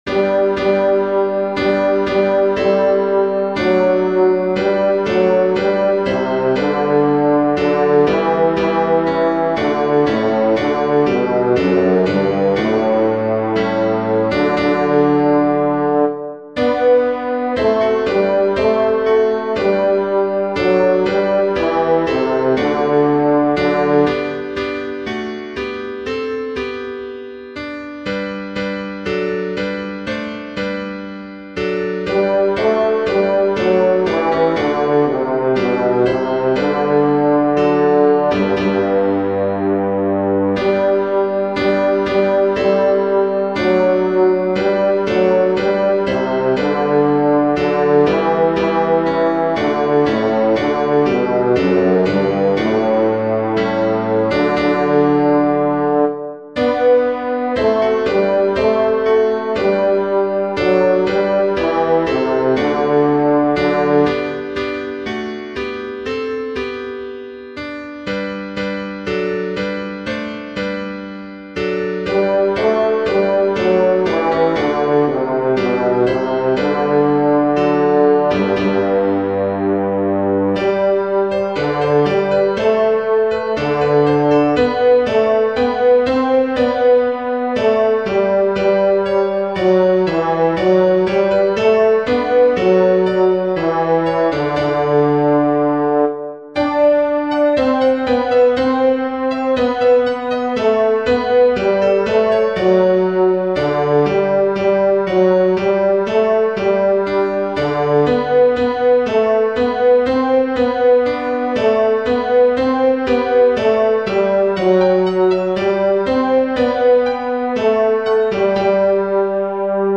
o_come_all_ye_faithful-bass.mp3